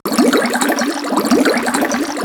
cauldron sound.
cauldron.ogg